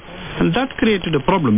描述：一个简短的演讲片段。男，英语，印度口音。 从特温特大学在线无线电接收器录制。
Tag: 讲话 谈话 印度口音 语音 印度男性 演讲 短波 特温特 收音机 敢-29 短期波 AM 印度英语 雄性